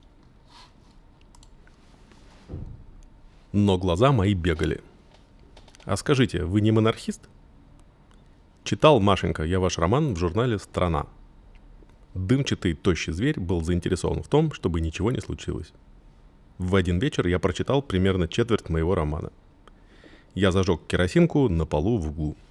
Оригинальный голос:
Артемий лебедев (дизайнер, блогер, путешественник)
Прослушать голос Артемий (Оригинал)
artemiy-original.mp3